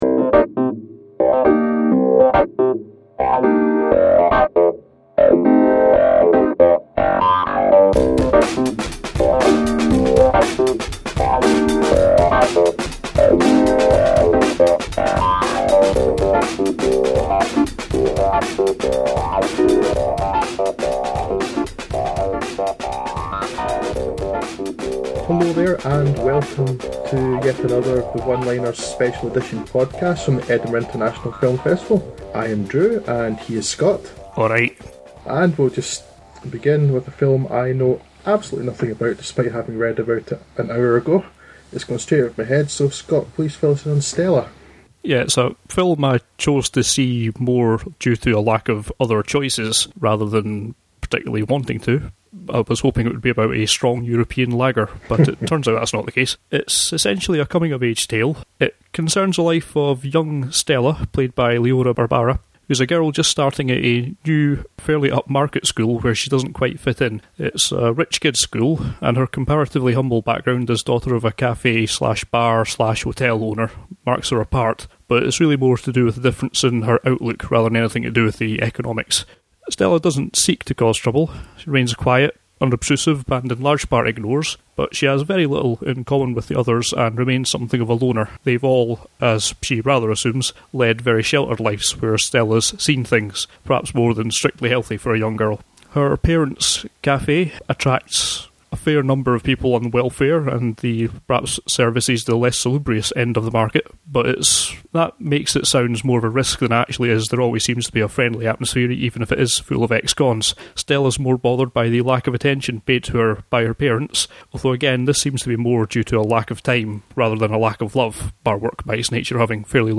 It's another podcast from the Edinburgh International Film Festival 2009! Five films covered, with a forty percent zombie hit-rate.